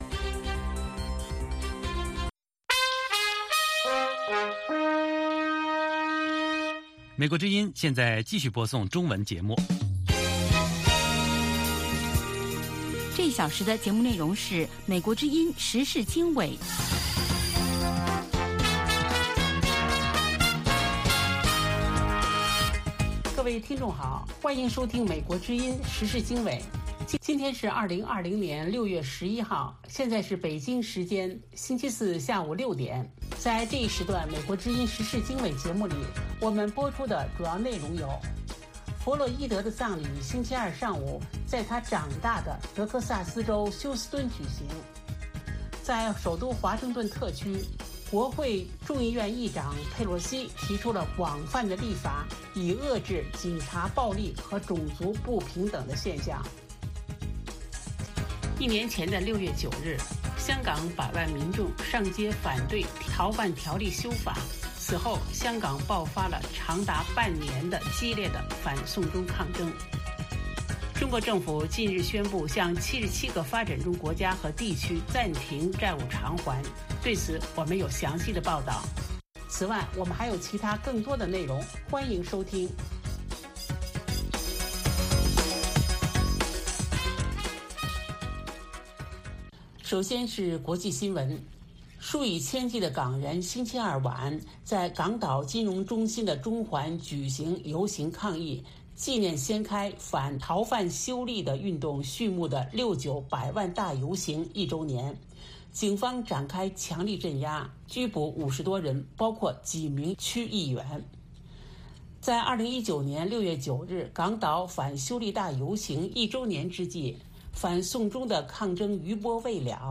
美国之音中文广播于北京时间每周一到周五晚上6-7点播出《时事经纬》节目。《时事经纬》重点报道美国、世界和中国、香港、台湾的新闻大事，内容包括美国之音驻世界各地记者的报道，其中有中文部记者和特约记者的采访报道，背景报道、世界报章杂志文章介绍以及新闻评论等等。